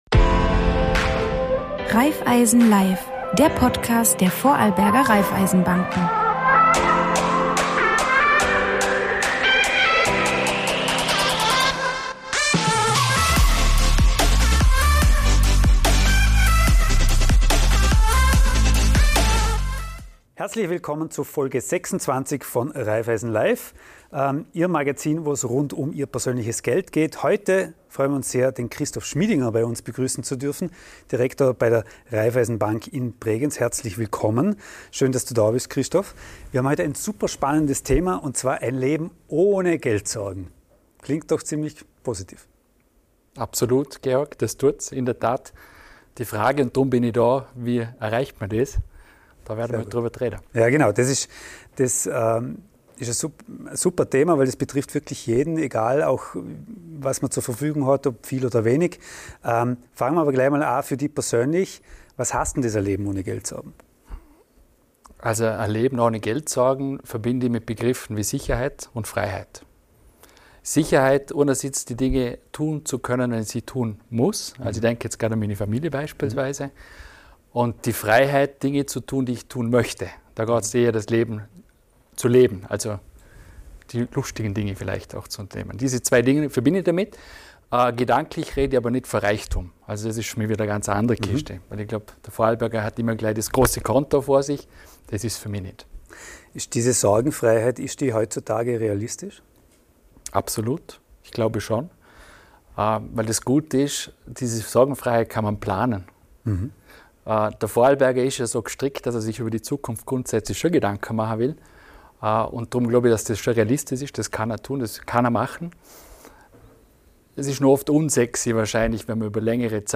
Außerdem geht es um den Einfluss von Inflation, nachhaltigen Investments und den Zinseszinseffekt. Ein Gespräch über Verantwortung, Chancen und darüber, warum Vorsorge kein trockenes Thema sein muss – sondern Lebensqualität bedeutet.